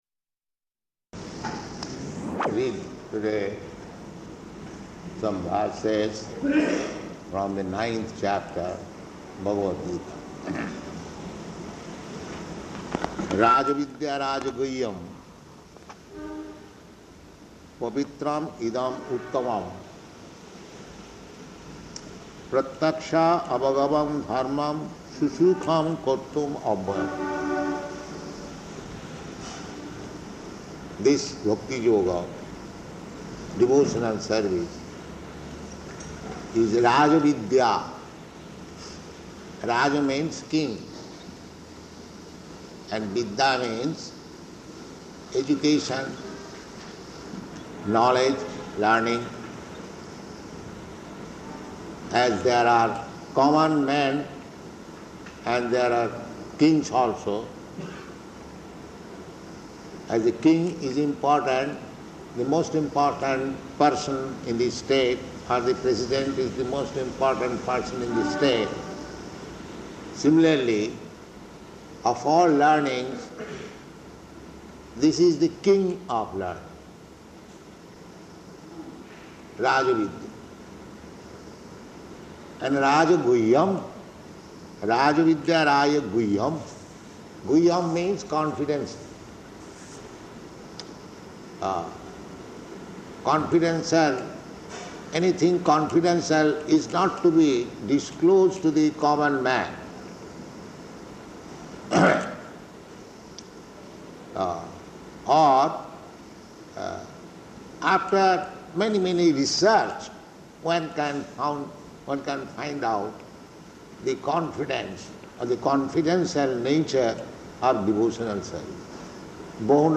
Type: Bhagavad-gita
Location: Calcutta